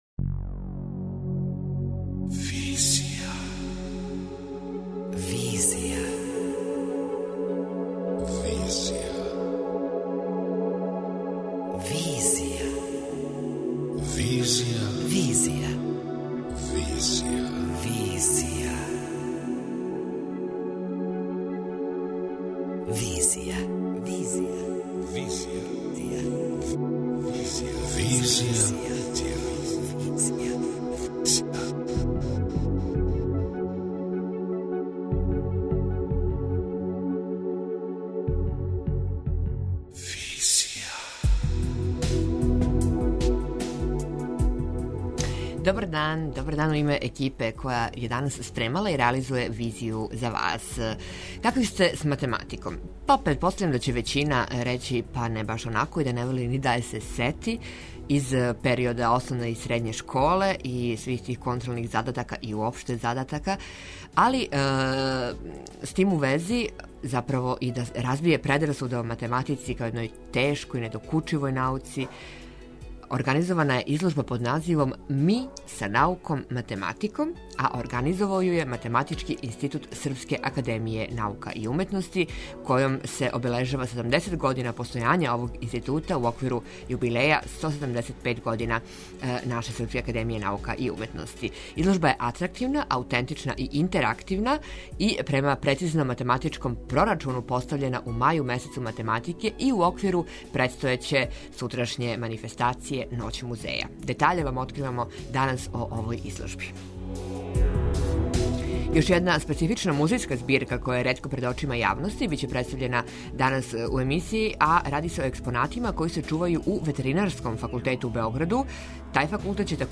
преузми : 27.09 MB Визија Autor: Београд 202 Социо-културолошки магазин, који прати савремене друштвене феномене.